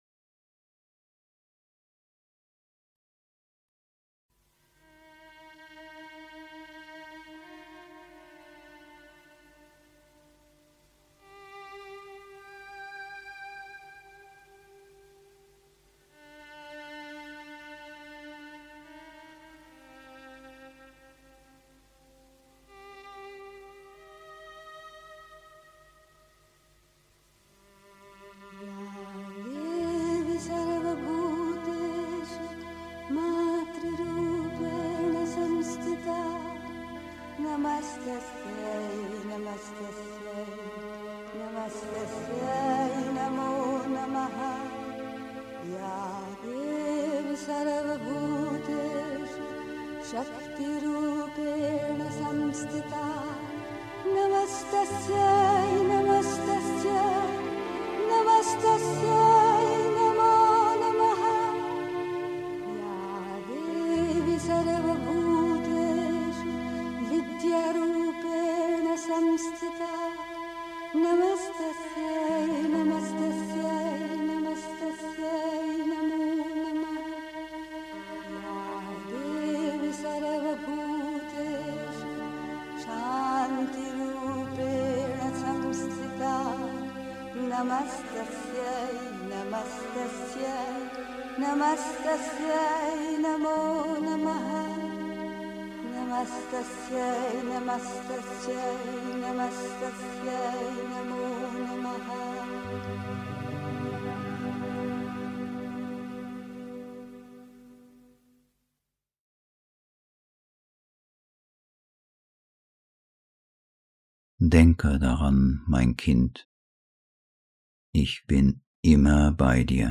Denke daran (Die Mutter, SHRINVANTU – 125th Birth Anniversary of The Mother) 3. Zwölf Minuten Stille.